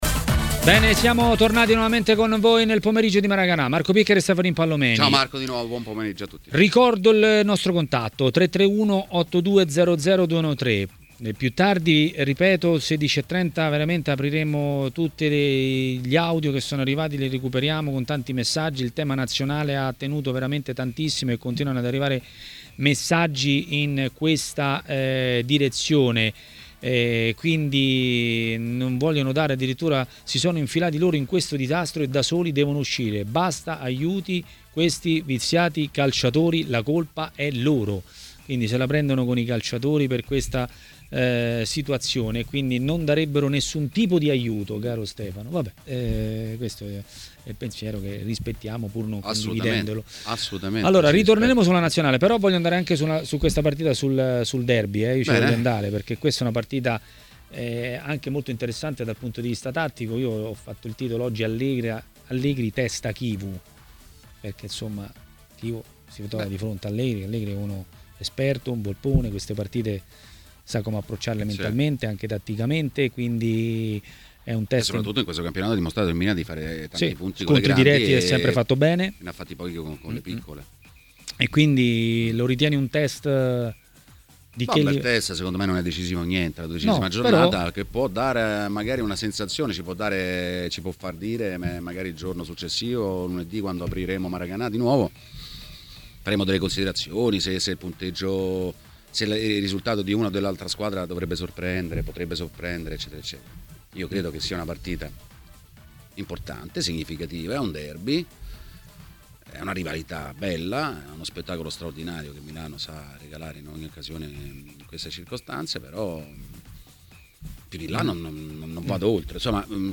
L'ex calciatore Robert Acquafresca è stato ospite di Maracanà, trasmissione di TMW Radio.